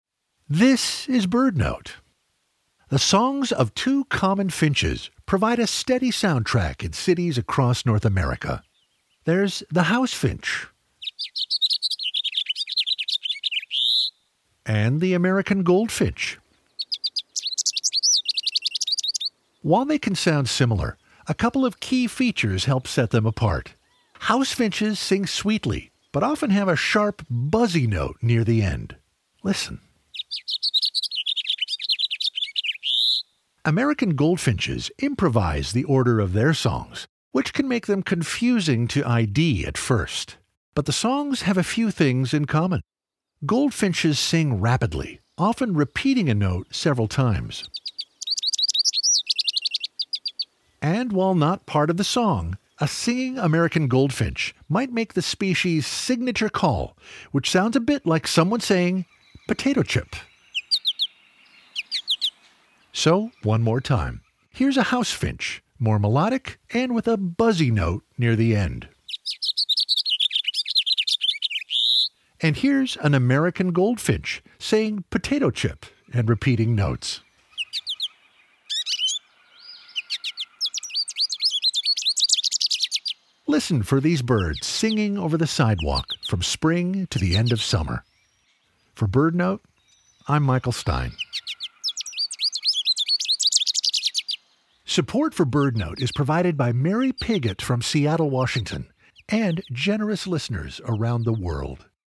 House Finches sing sweetly but often have a sharp, buzzy note near the end. Goldfinches sing rapidly, often repeating a note several times. They also often make their distinctive call, which sounds like someone quickly saying “potato chip!”